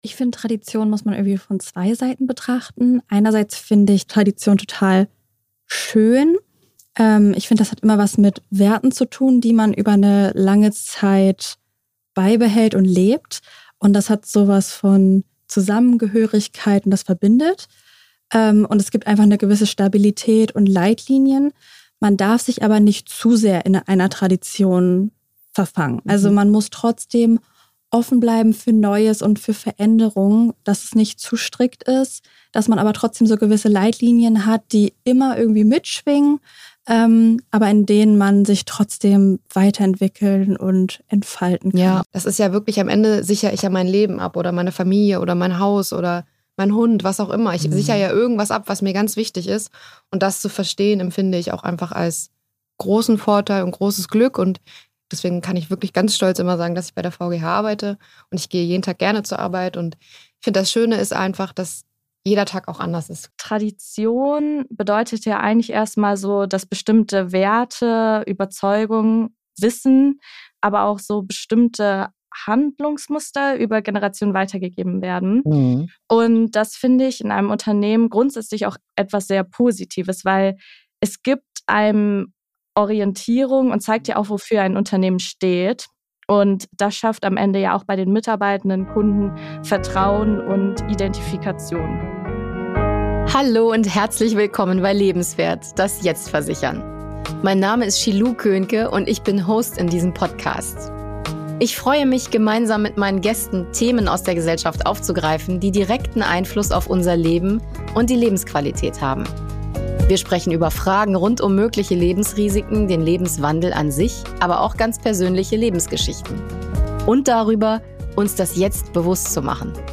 In dieser Folge sprechen wir mit drei jungen Menschen, die uns als Berufseinsteiger in der Versicherungswelt Antworten liefern! Als Vertreterinnen der Gen Z berichten sie, was sie in die Versicherungsbranche geführt hat, wie sie den bekannten Vorurteilen gegenüber Versicherern begegnen und welche Rolle Werte wie Stabilität, Sicherheit und Tradition heute noch für sie spielen.